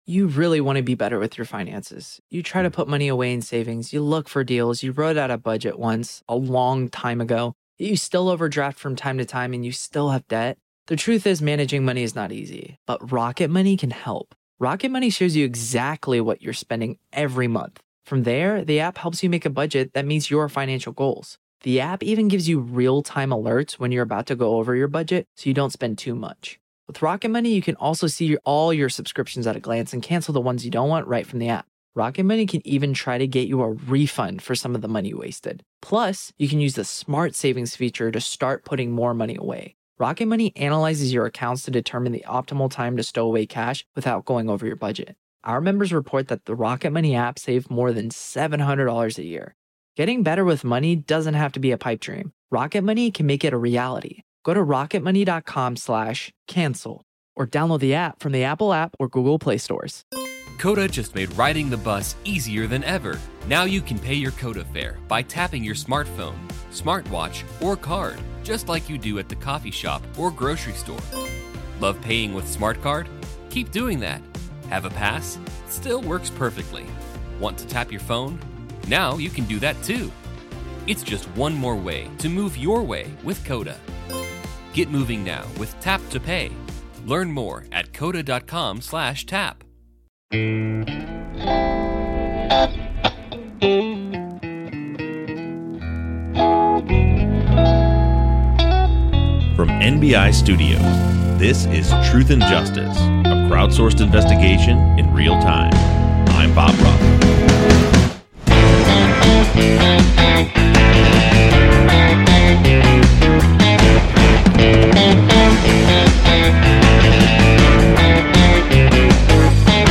full interview. Grab a pen and paper, because this one is chock-full of information.